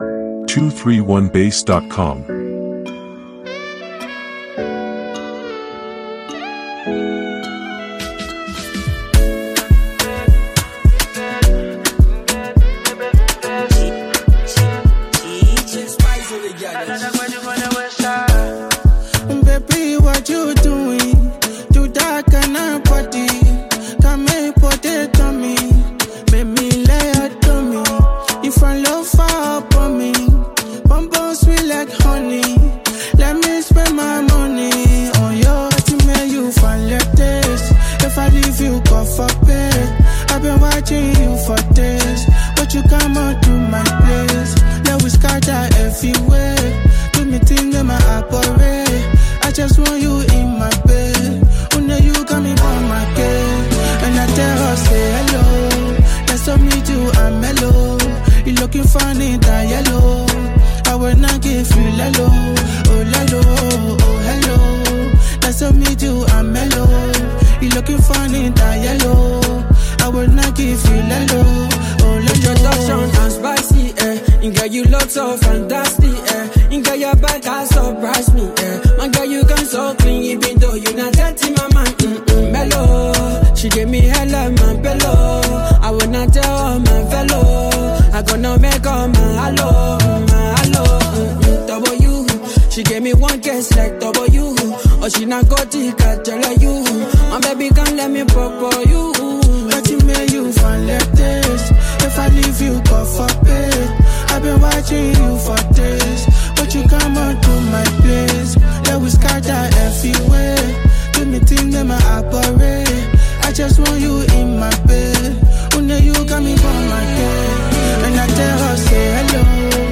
a sweet serenade
their voices blending like a warm hug on a chill night.